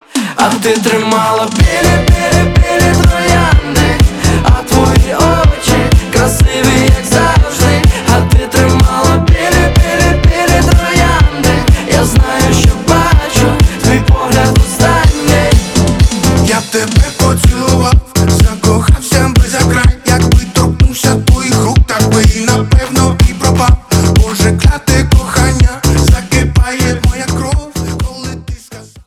• Качество: 320, Stereo
громкие